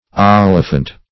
Search Result for " olifant" : The Collaborative International Dictionary of English v.0.48: Olifant \Ol"i*fant\, n. [OF.] 1.